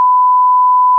題材：2つのサイン波を分類する音声認識モデル
1000Hzダウンロード
1000Hz.wav